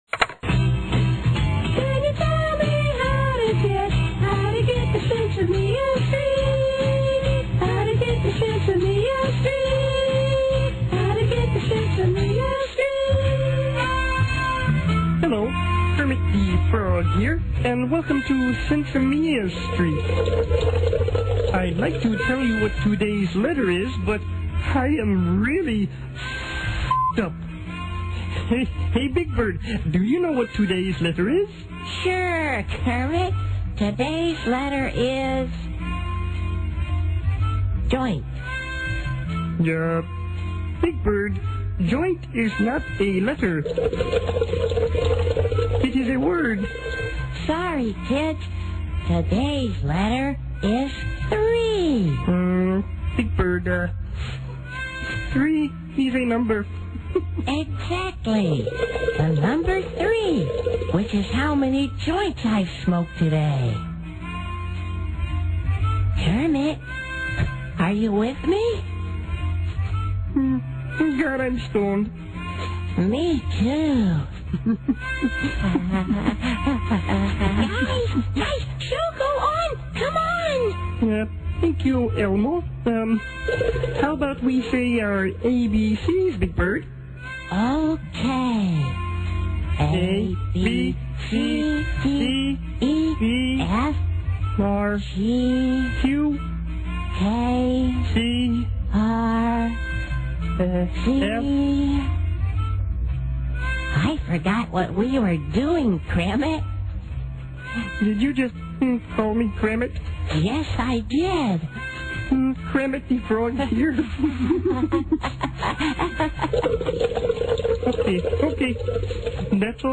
1960's folk